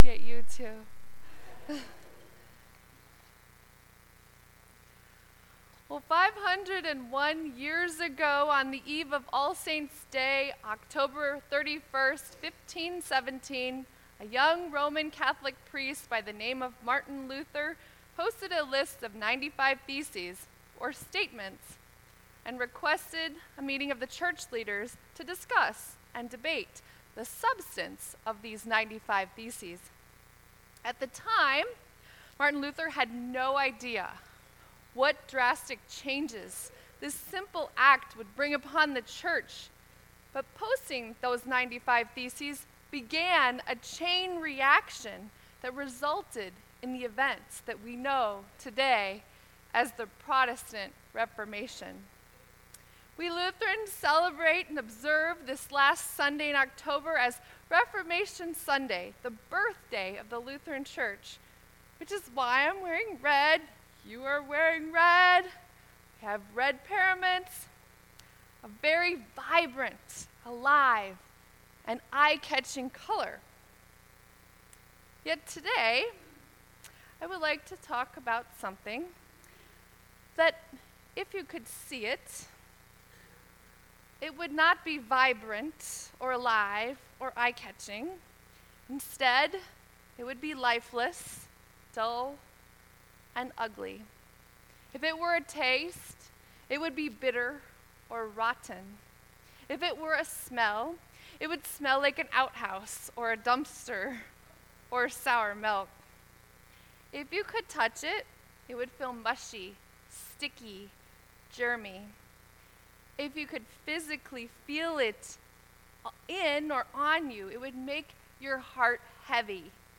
Sermon 10.28.2018